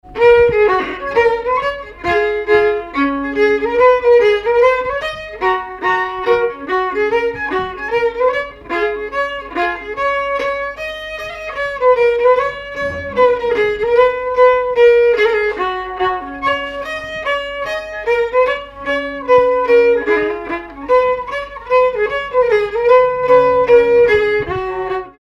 Mémoires et Patrimoines vivants - RaddO est une base de données d'archives iconographiques et sonores.
Mazurka
danse : mazurka
circonstance : bal, dancerie
Pièce musicale inédite